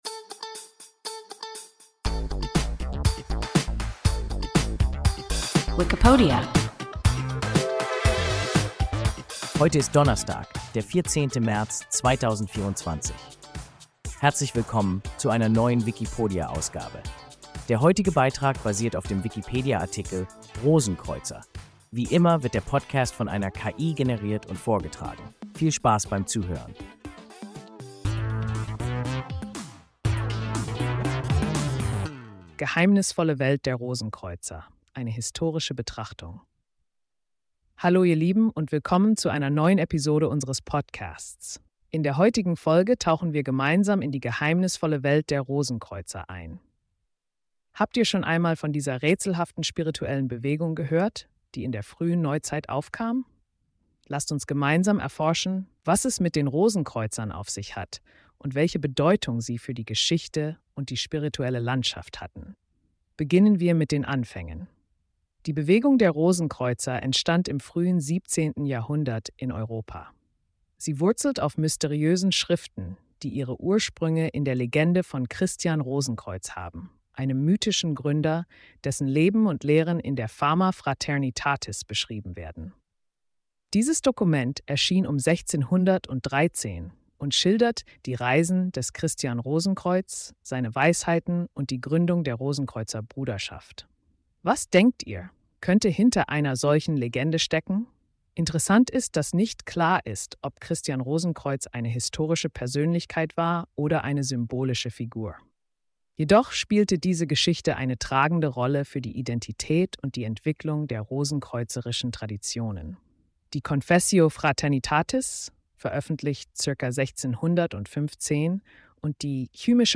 Rosenkreuzer – WIKIPODIA – ein KI Podcast